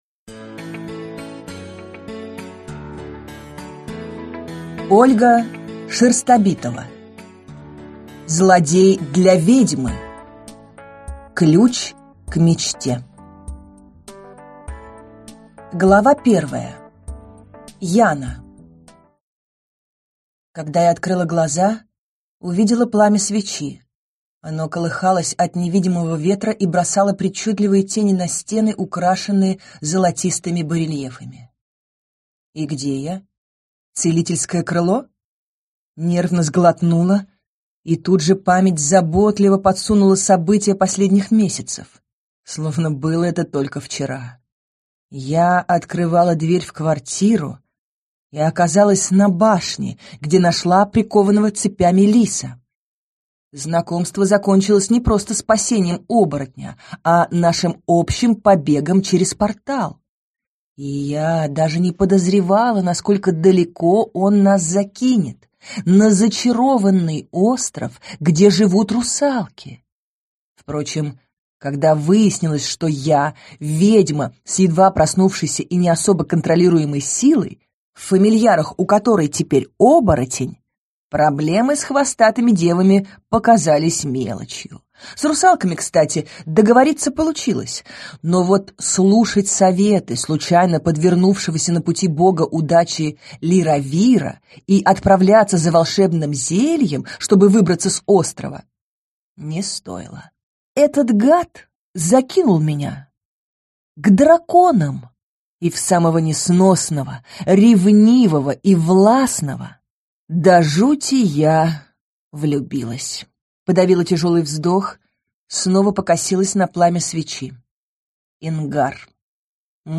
Аудиокнига Злодей для ведьмы.